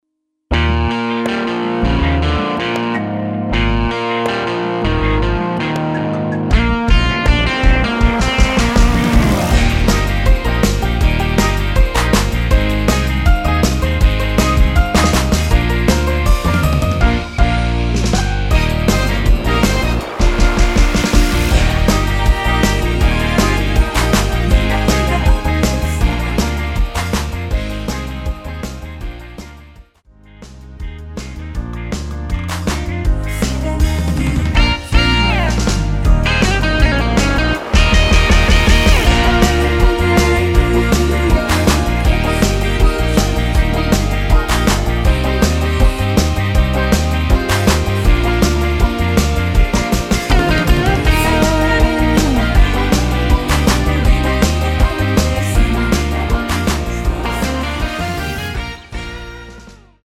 Eb
◈ 곡명 옆 (-1)은 반음 내림, (+1)은 반음 올림 입니다.
앞부분30초, 뒷부분30초씩 편집해서 올려 드리고 있습니다.